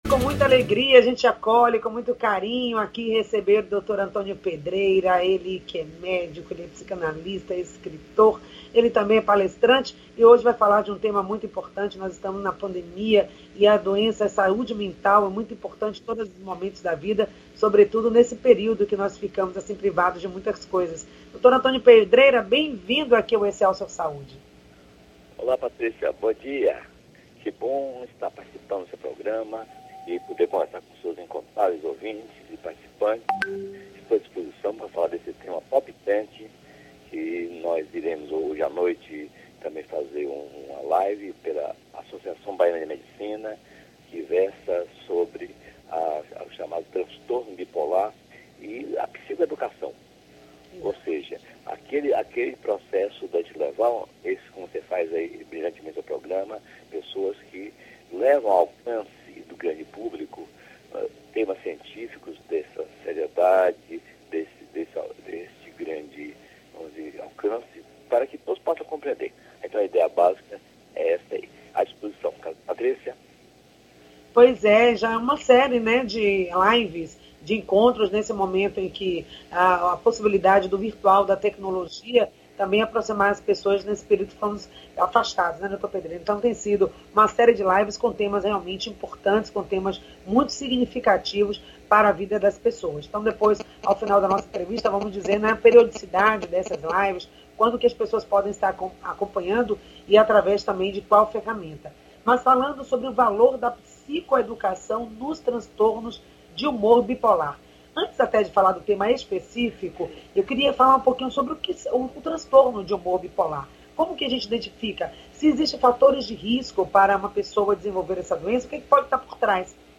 O programa Excelsior Saúde, acontece das 9h às 10h, ao vivo com transmissão pela Rádio Excelsior AM 840.